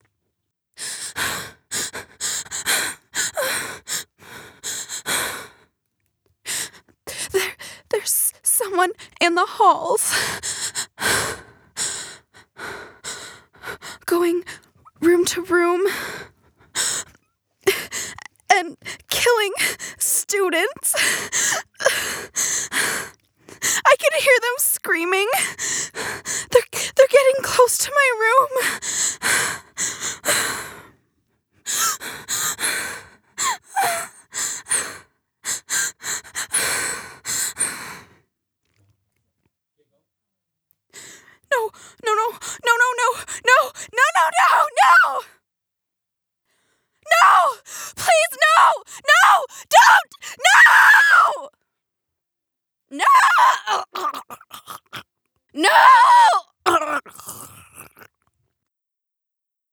DORM is set in a college dormitory where the player is trapped in their room looking for clues and ways to escape down the hall while a murderer is going room to room. A short clip of the voice acting acting:
victoria-college-girl-Booth-dorm-recording-cut.wav